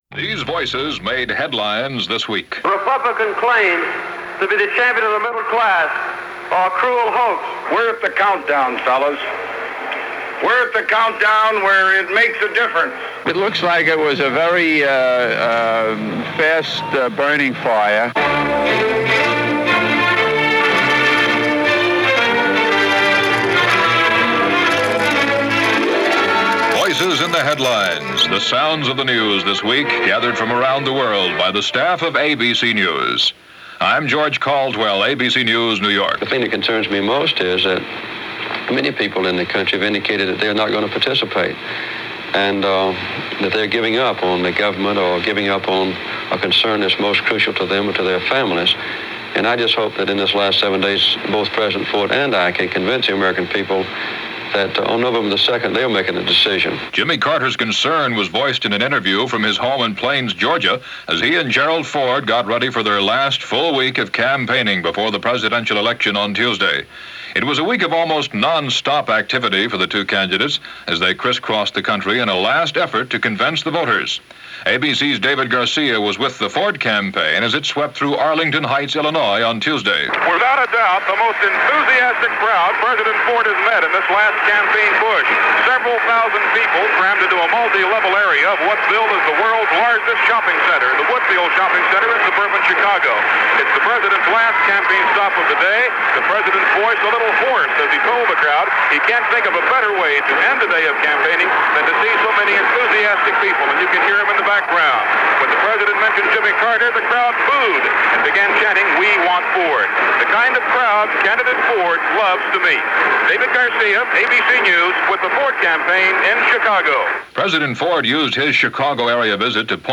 Election '76: A Dead Heat, Tapes For Sale And The Possibility Of Poison - October 31, 1976 - ABC World News This Week.